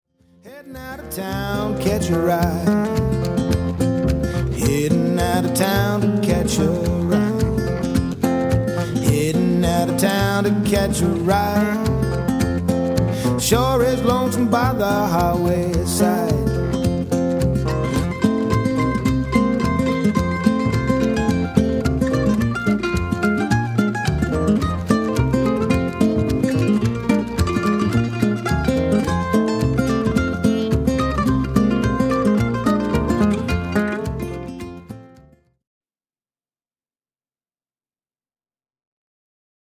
Recorded at Pegasus Studio, Cairns